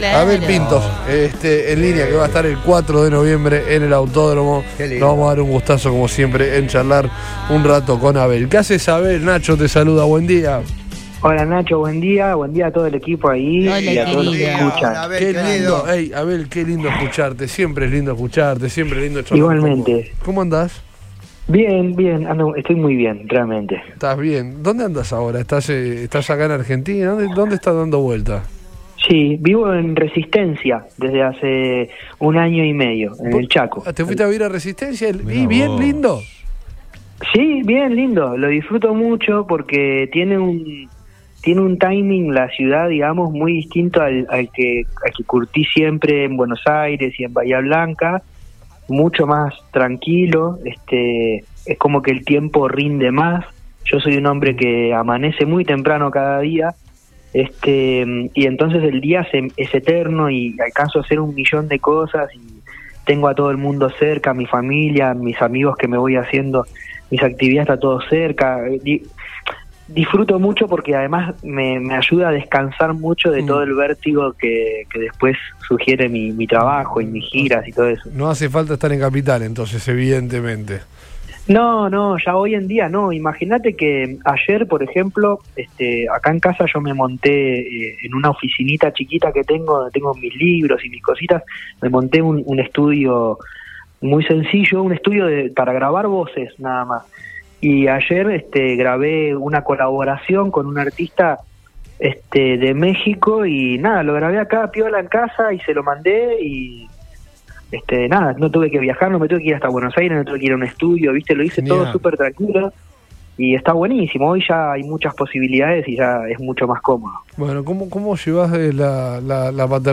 En la previa de su presentación en Rosario, el cantante dialogó con Todo Pasa por Radio Boing, donde dio pormenores del show que vendrá a realizar al autódromo de la ciudad.